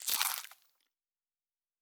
Food Eat 03.wav